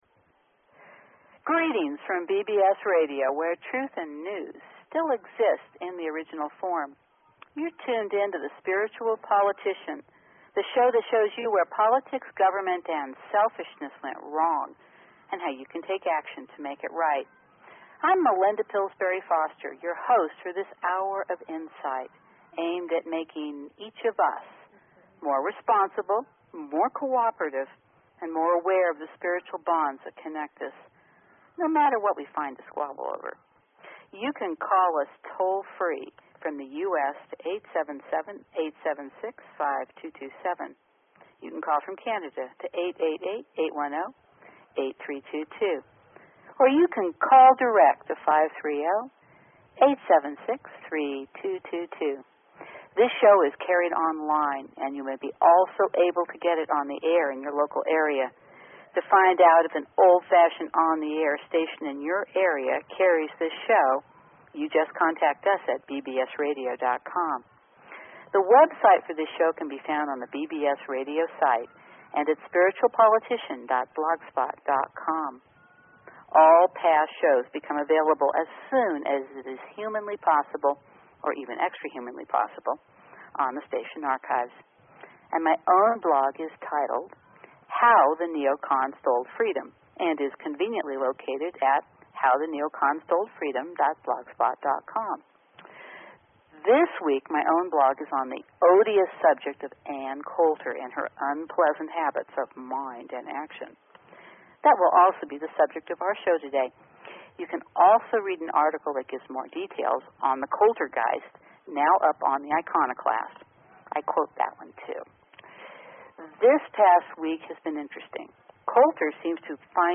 Talk Show Episode, Audio Podcast, Spiritual_Politician and Courtesy of BBS Radio on , show guests , about , categorized as